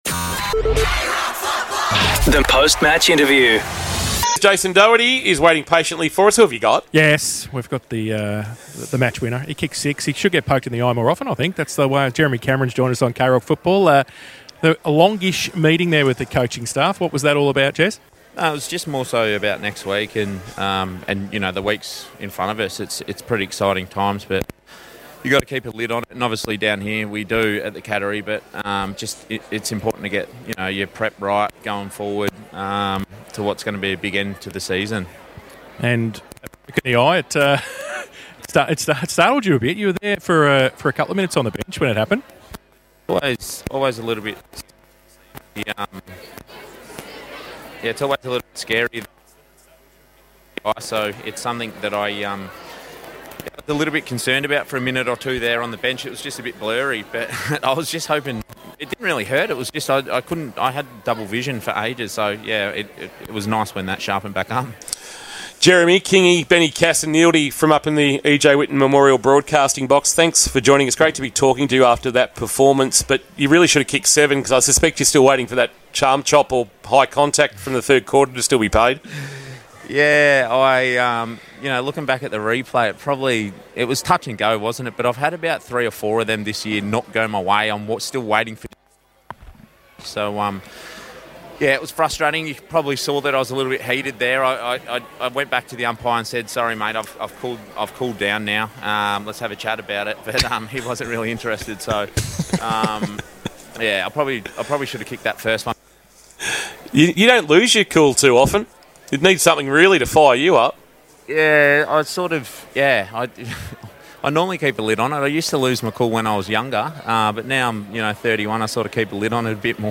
2024 - AFL - Round 21 - Geelong vs. Adelaide - Post-match interview: Jeremy Cameron (Geelong Cats)